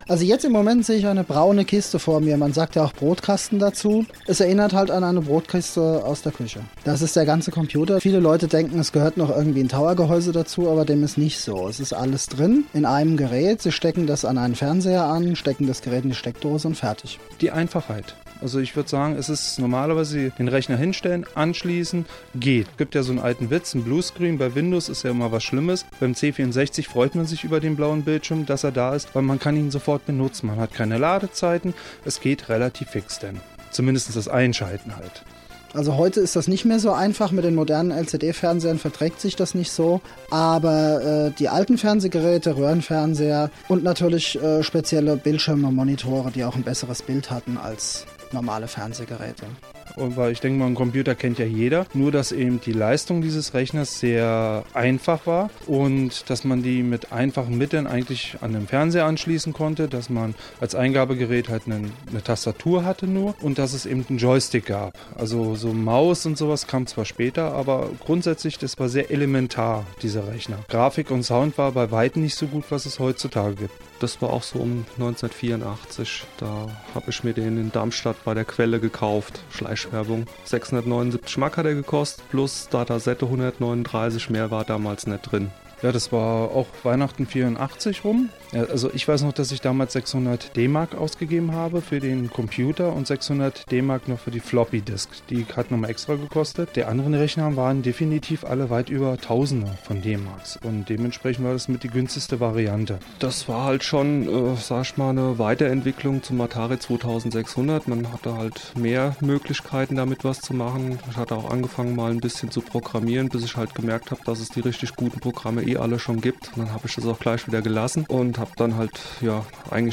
Unser Radiointerview in fast voller Länge, neu zusammengeschnitten.
Es ist hier ohne die Fragen, aus dem Zusammenhang gerissen und neu gemixt.